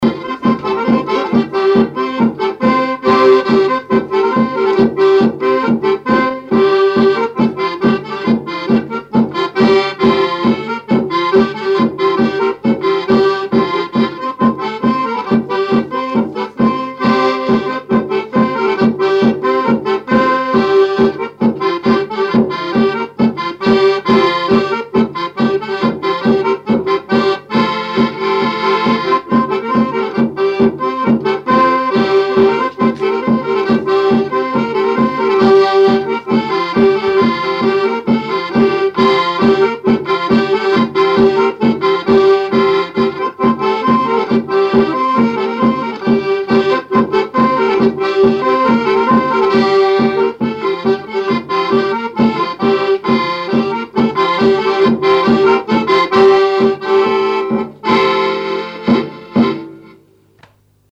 Couplets à danser
branle : courante, maraîchine
répertoire à l'accordéon chromatique et grosse caisse
Pièce musicale inédite